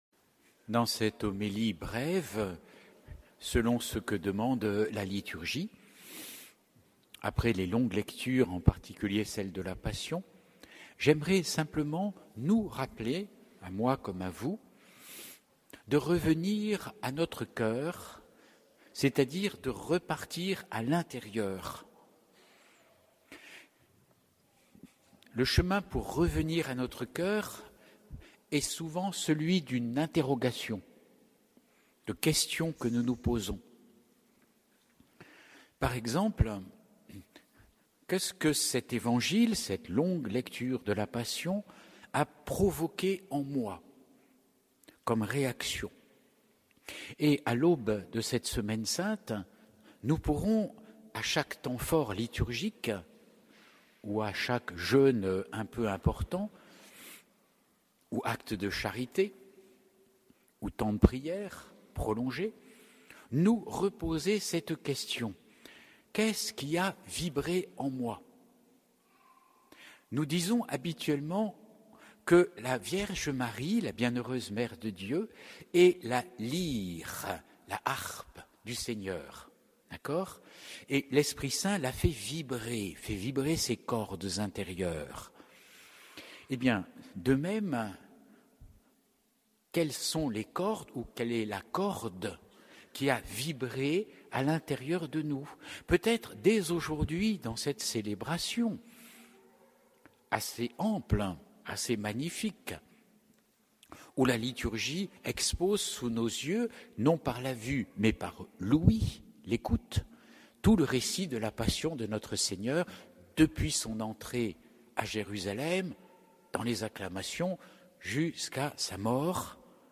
Homélie du dimanche des Rameaux et de la Passion - Année C - Monseigneur Luc Ravel (2025)